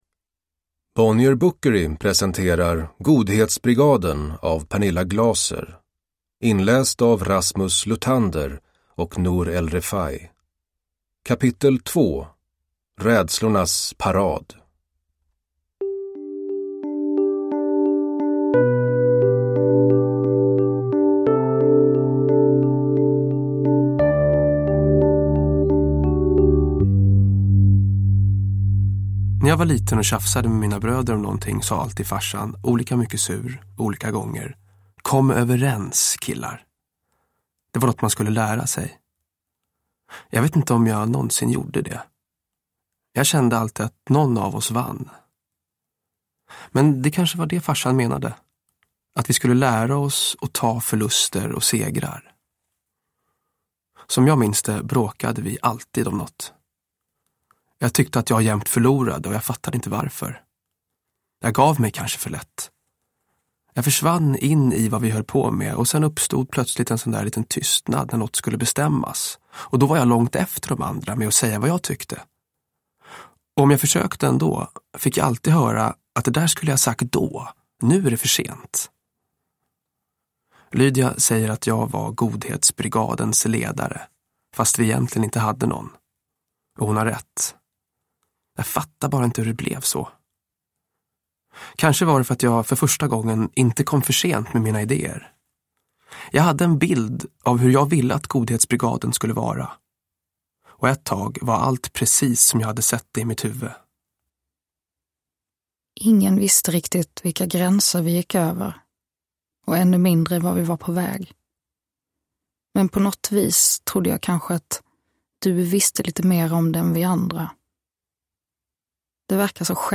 Godhetsbrigaden. S1E2, Rädslornas parad – Ljudbok – Laddas ner